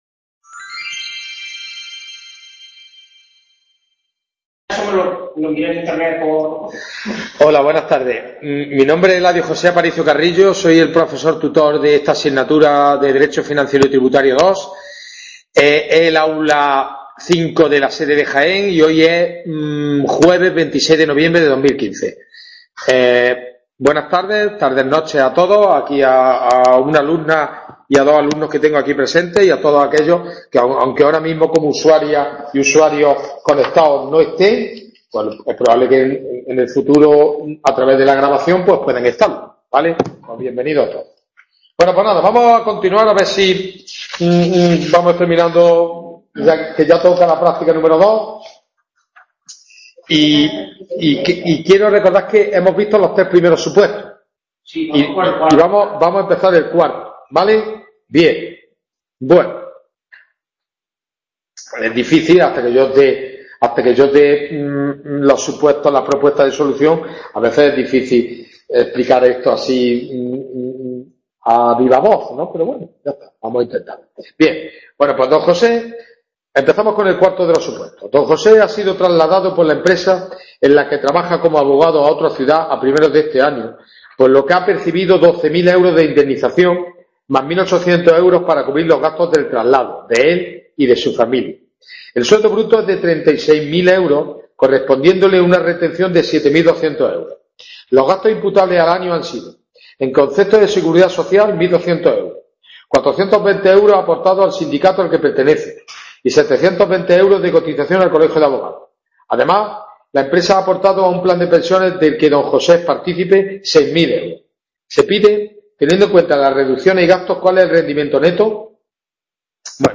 WEB CONFERENCIA
AULA 5 DE LA SEDE DE JAÉN.